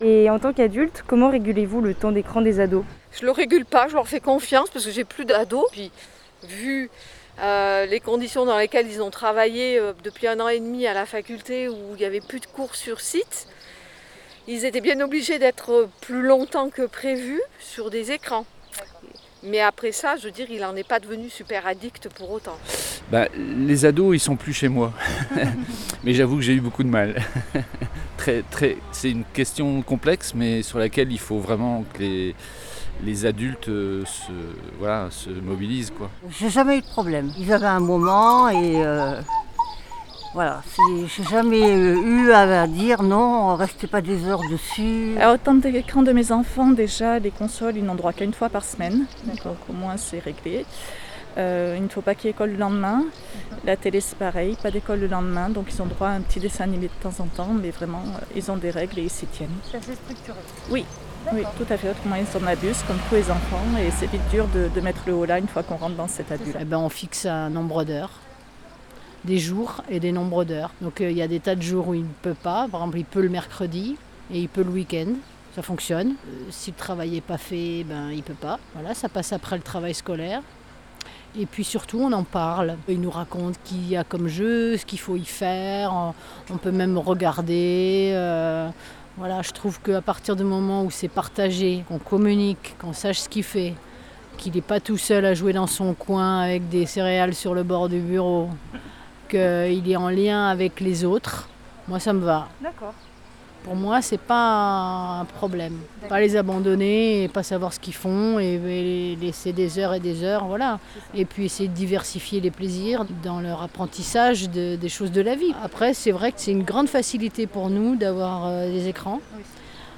Un micro-trottoir réalisé sur le marché de Dieulefit auprès d’adultes et de parents, autour d’une question : Adultes, comment régulez vous le temps d’écran des ados ?